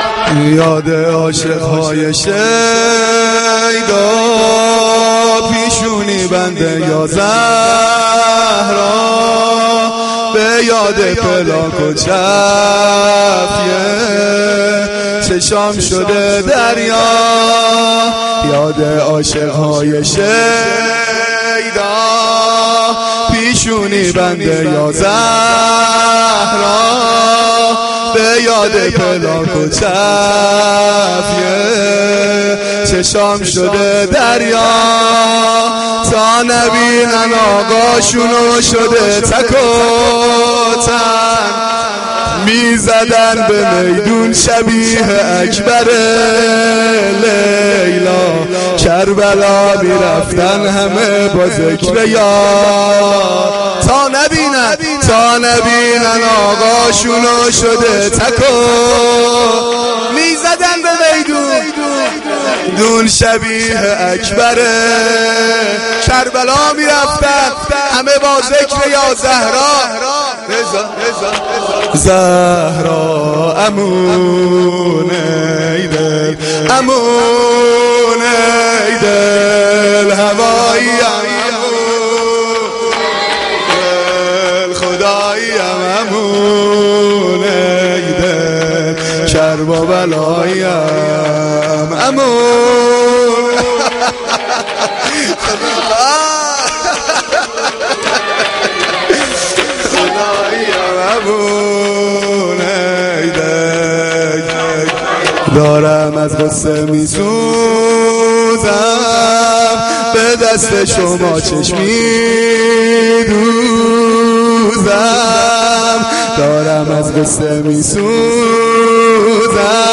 مداحی
Shab-9-Moharam-6.mp3